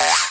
BP_14_SFX_Rope_Miss.ogg